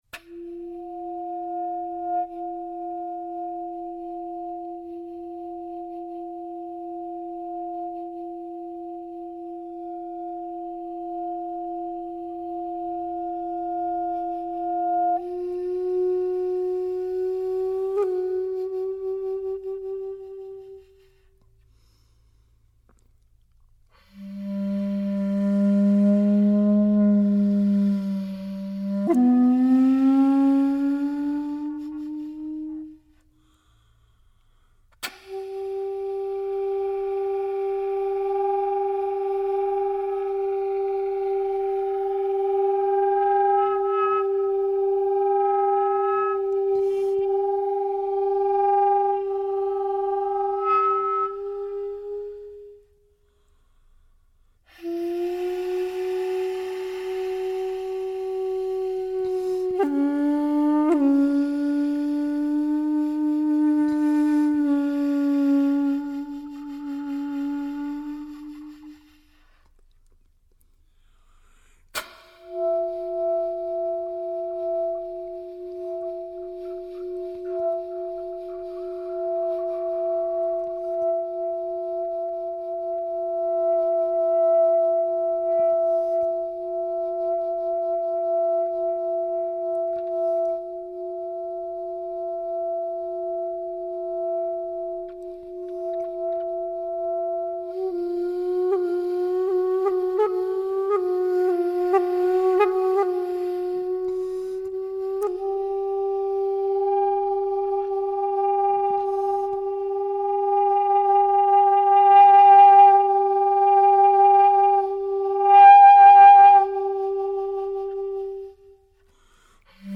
solo Clarinet